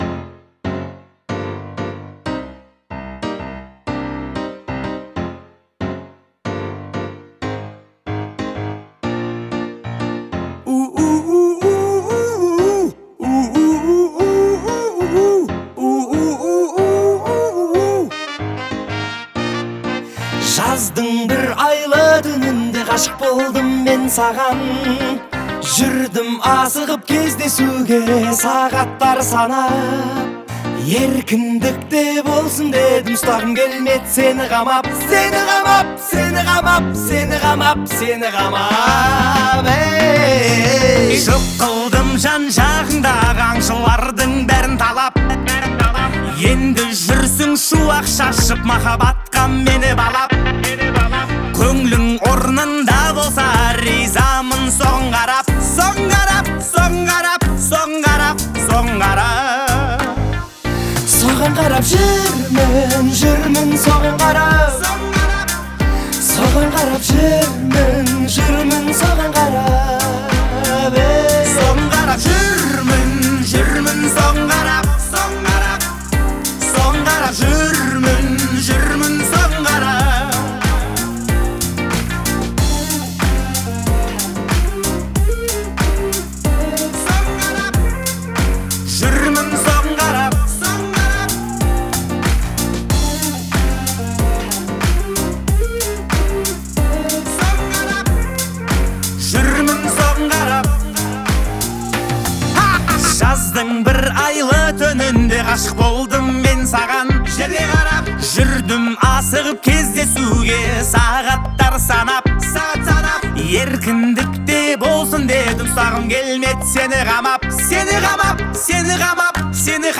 это яркая композиция в жанре казахской поп-музыки